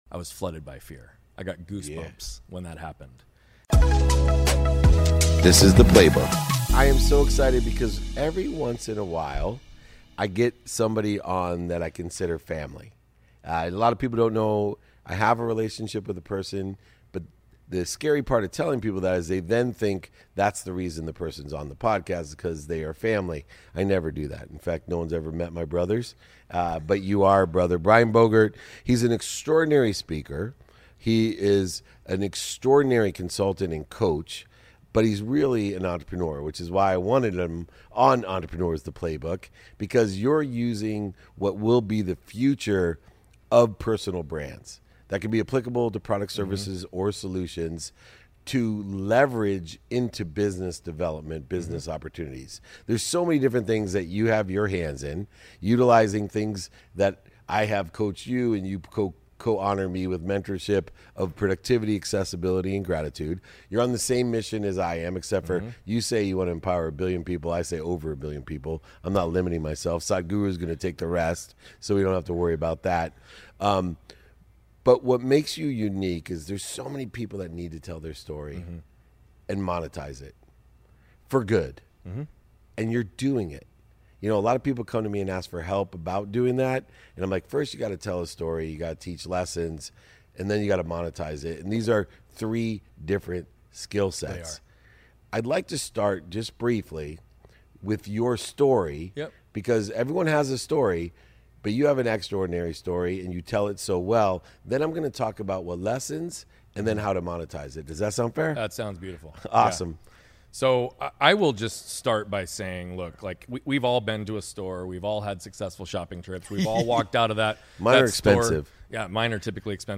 Eliminating Fear to Find Success | Interview